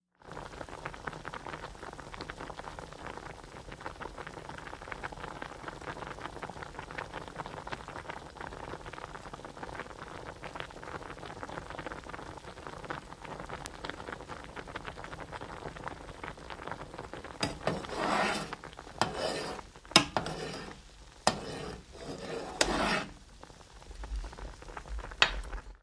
Шум кипящего супа на плите